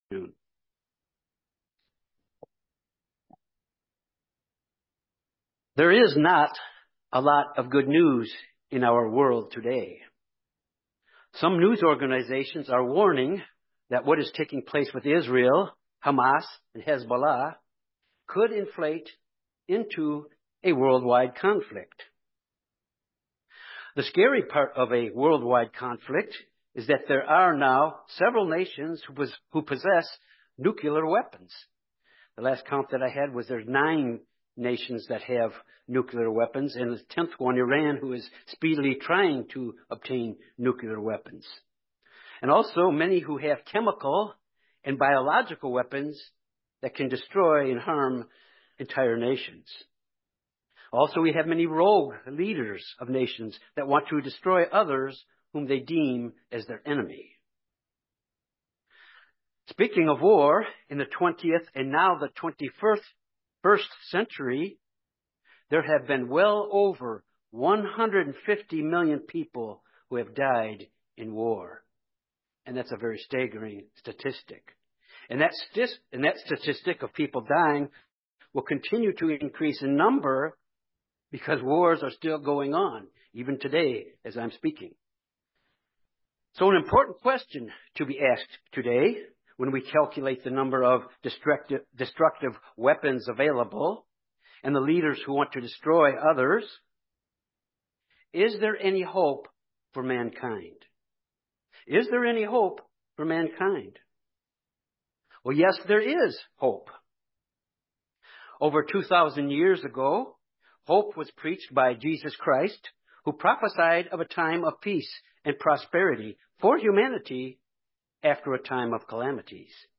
This Bible study examines our need to always keep our focus on the Kingdom. Seek first the Kingdom and pray God’s Kingdom come.